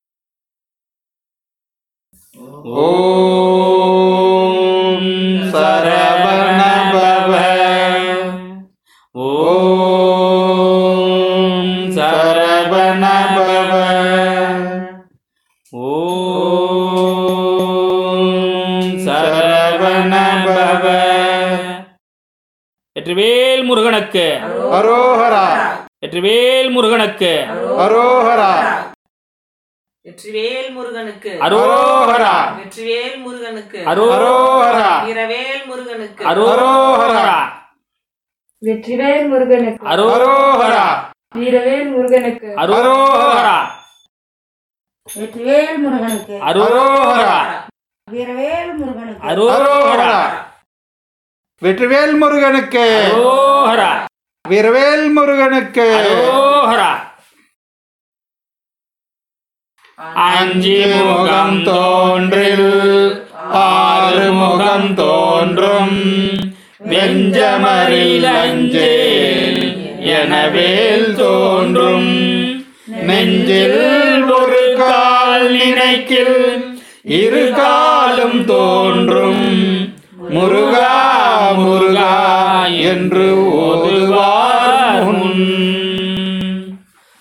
பாடியவர்கள்:    சங்கீர்த்தன இயக்கம் பக்த்தர்கள்